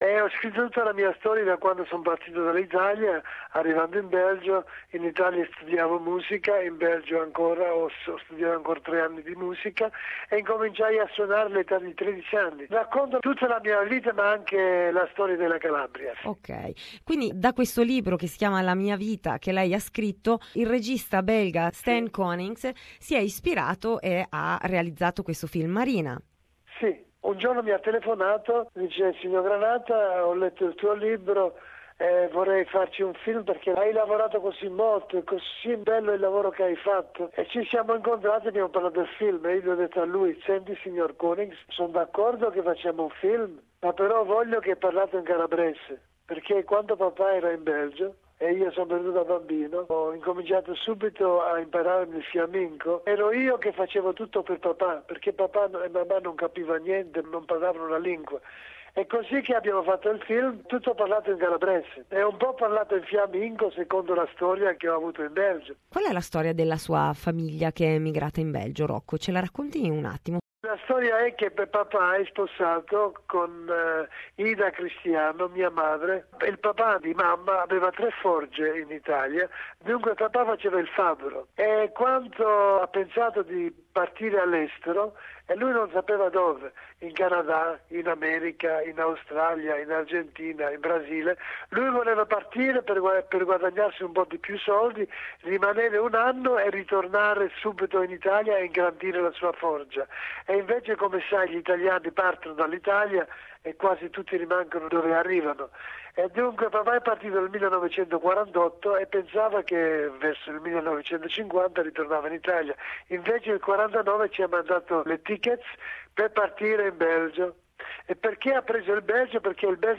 This years Opening Night Selection is "Marina", a movie that tells the true story of Rocco Granata, singer, songwriter and accordionist, from his early life as an immigrant in Belgium to his emergence as a worldwide musical phenomenon with his 1959 song Marina. You can listen here the interview with Rocco Granata.